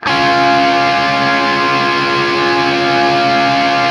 TRIAD C# L-R.wav